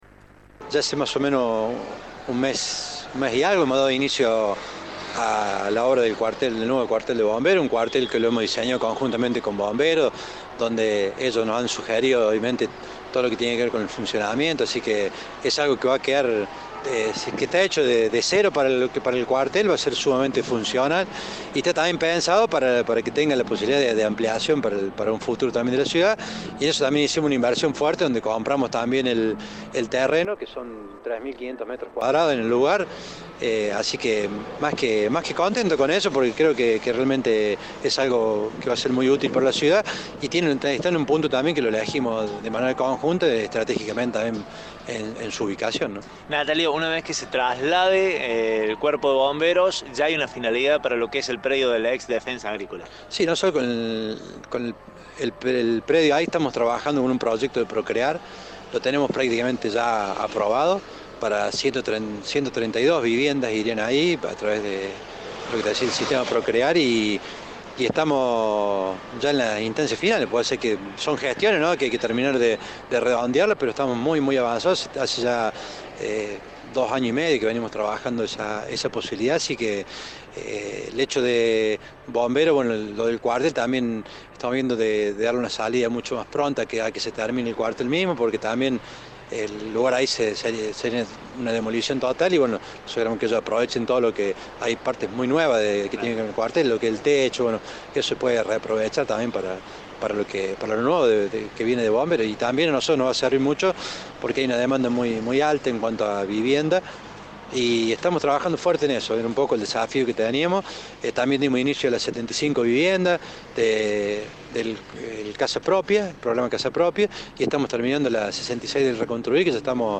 El intendente de Villa Nueva, Natalio Graglia, habló con nuestro medio y brindó detalles del nuevo Cuartel de Bomberos que se está construyendo.